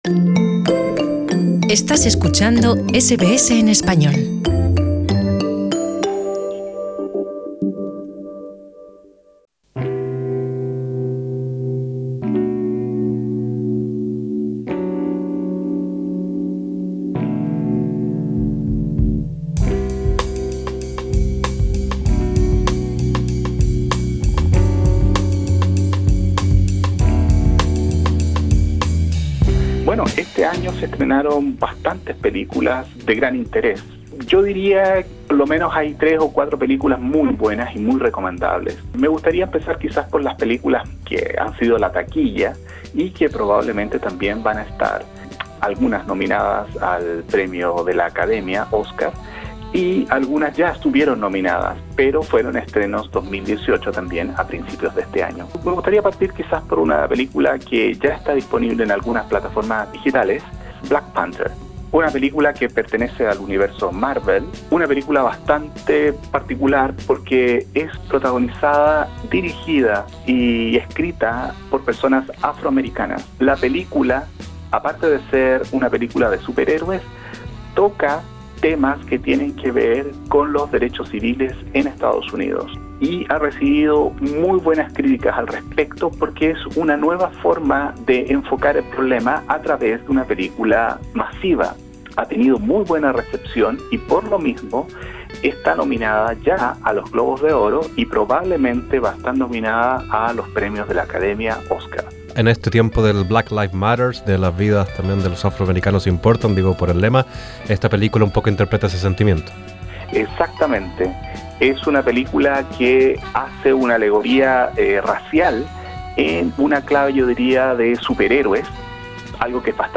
quien habla con Radio SBS de lo mejor del cine estrenado este año 2018.